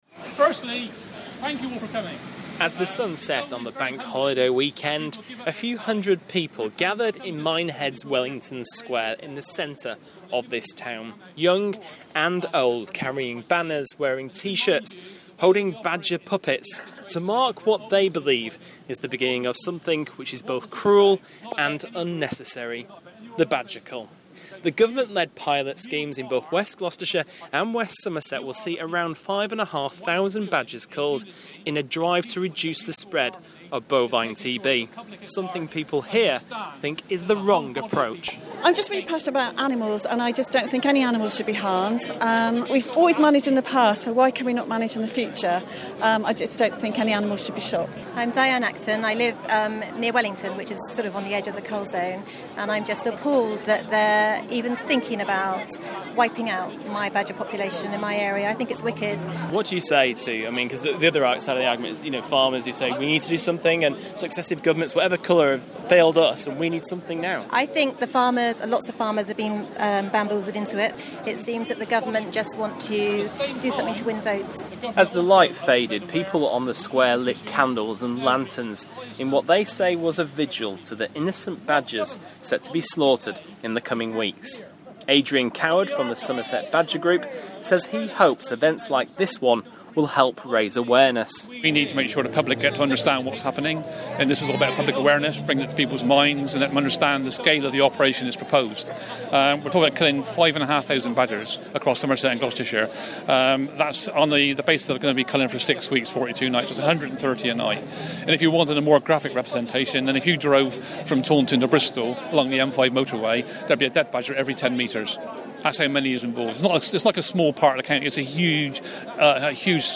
speaks to anti badger cull campaigners in Minehead.